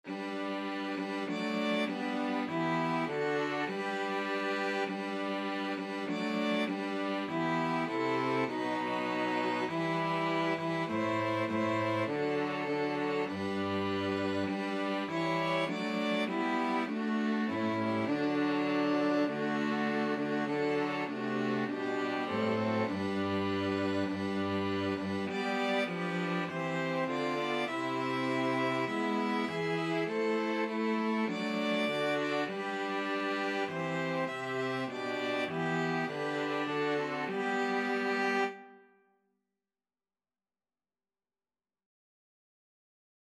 Christian Christian String Quartet Sheet Music Come, ye thankful people, come (St George)
G major (Sounding Pitch) (View more G major Music for String Quartet )
4/4 (View more 4/4 Music)
String Quartet  (View more Intermediate String Quartet Music)
Classical (View more Classical String Quartet Music)